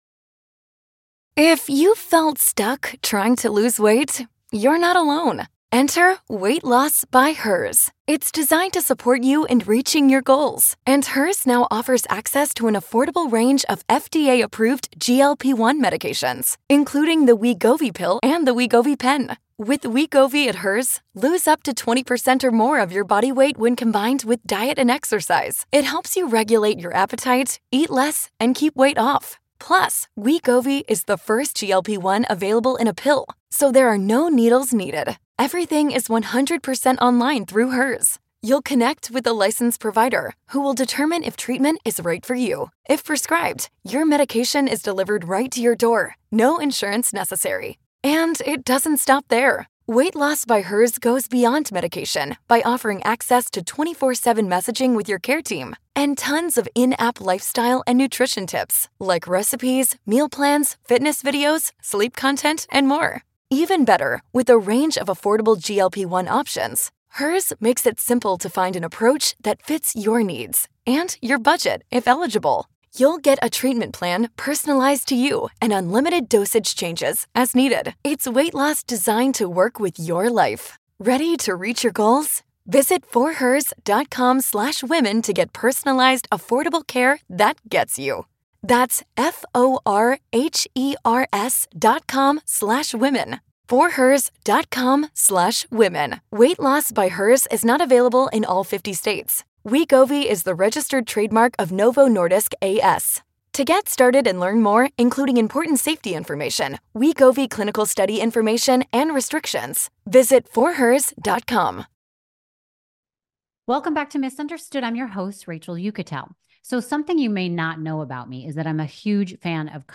Singer, songwriter and all around cool guy, Niko Moon, is prepping to take his music on tour in 2024 and release his second album, Better Days.